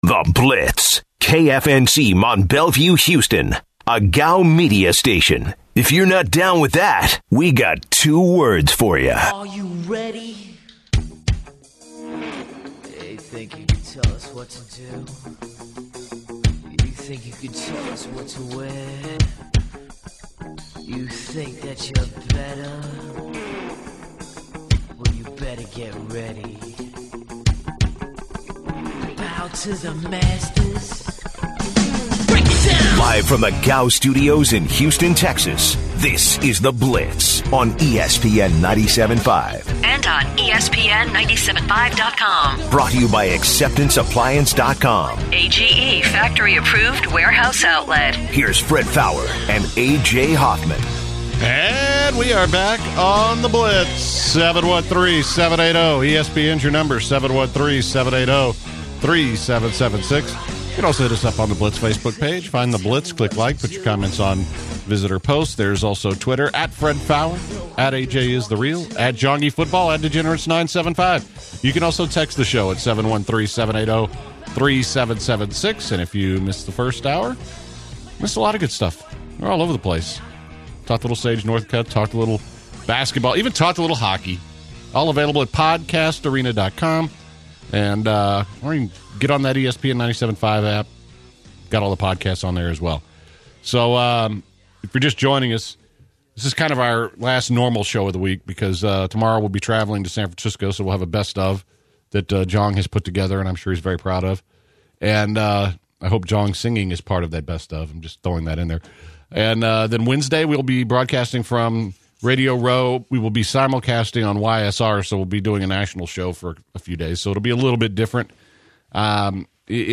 Former NFL and UH cornerback Stanford Routt joins the show to discuss Super Bowl 50, Peyton Manning pending retirement, Cam Newton image around the NFL, and the difference between Russell Wilson and Cam Newton.